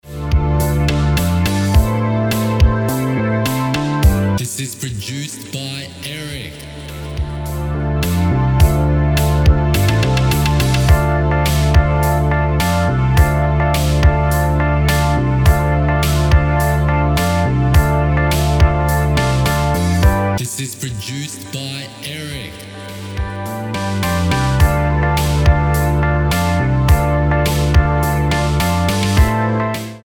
Featuring not one, but two classic electric pianos.
Key: D minor Tempo: 105BPM Time: 4/4 Length: 3:39